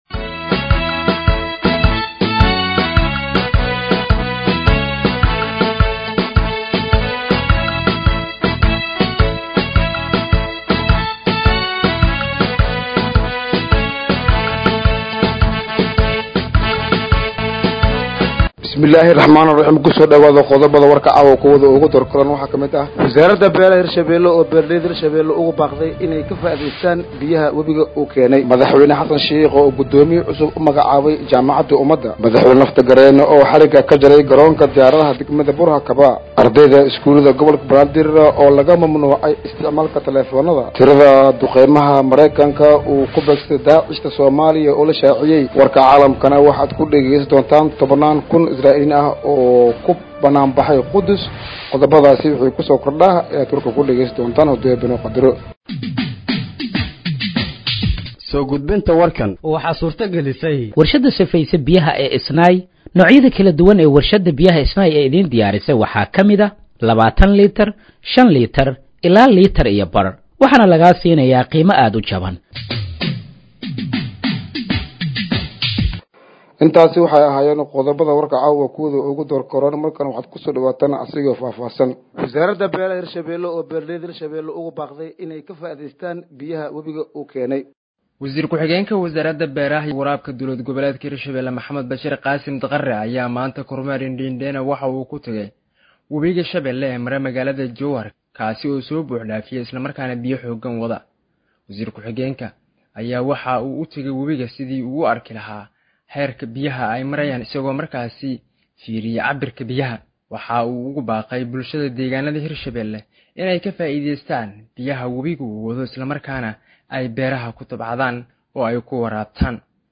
Dhageeyso Warka Habeenimo ee Radiojowhar 07/09/2025